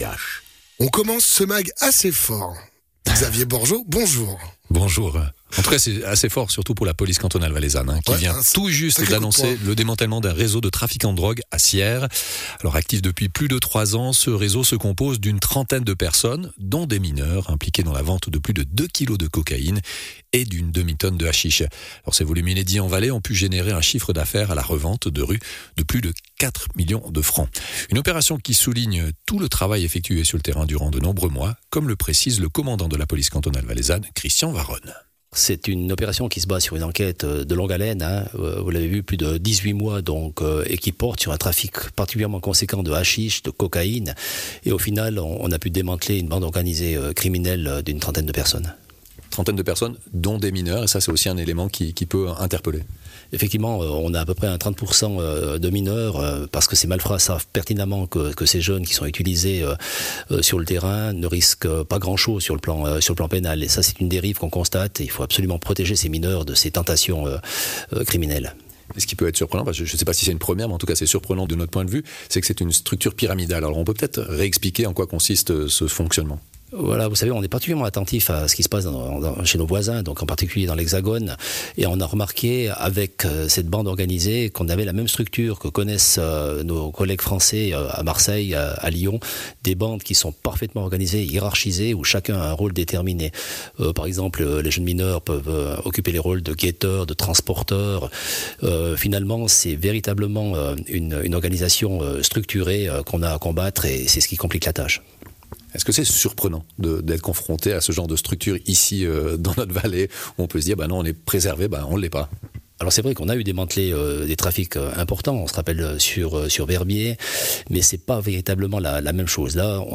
Intervenant(e) : Christian Varone, Commandant de la Police cantonale valaisanne